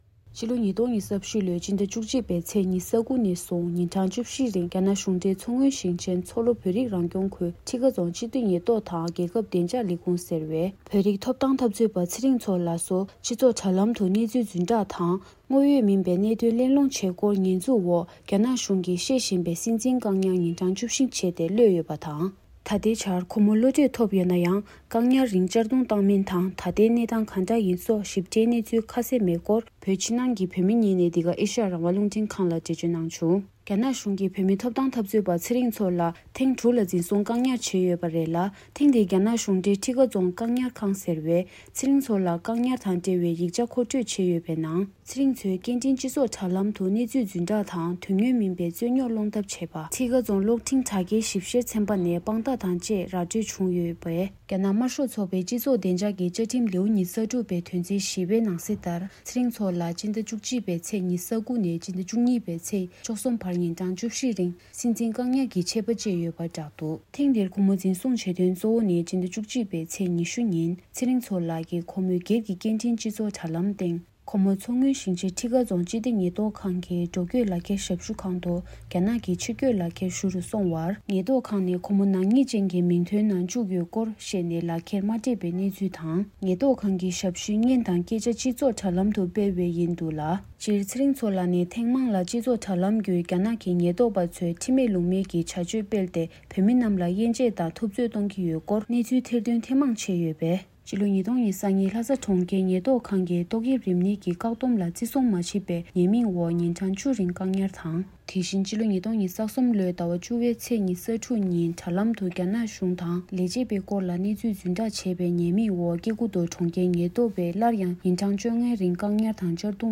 བོད་ཕྱི་ནང་གི་བོད་མི་ཁག་ཅིག་གིས་འདི་ག་ཨེ་ཤེ་ཡ་རང་དབང་རླུང་འཕྲིན་ཁང་ལ་འགྲེལ་བརྗོད་གནང་བྱུང་།